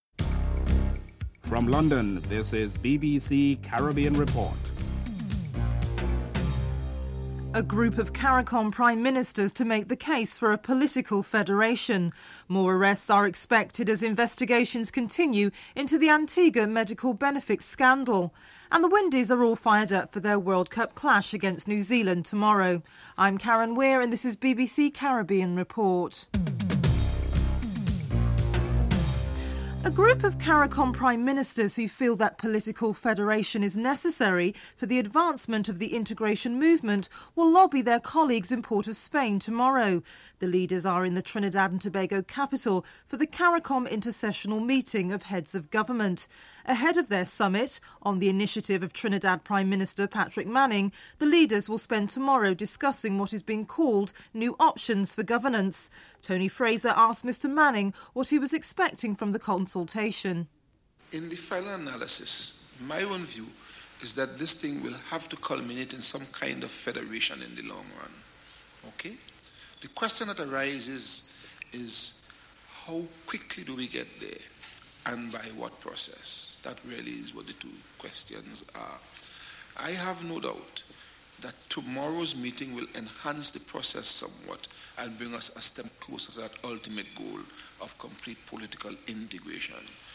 Prime Minister Patrick Manning describes his expectations.
David Laws, Liberal Democrat MP is on the Parliamentary Select Committee and hears evidence on the matter (11:07-12:24)